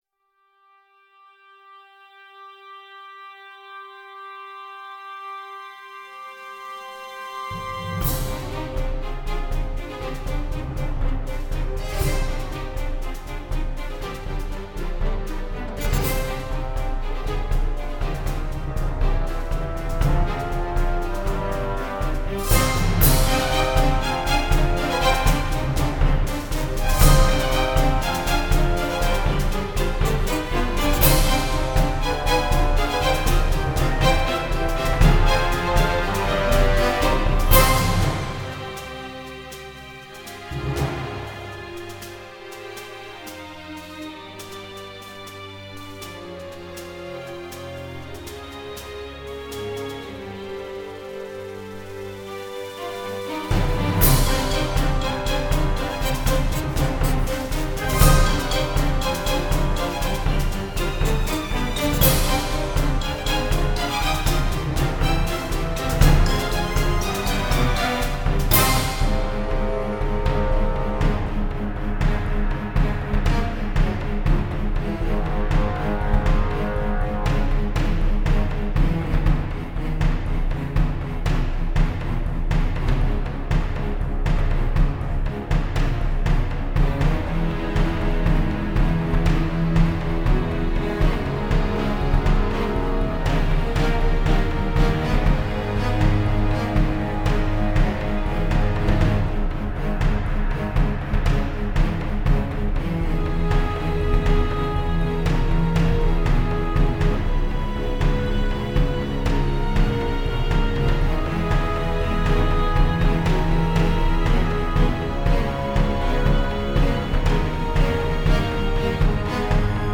soundtrack/game music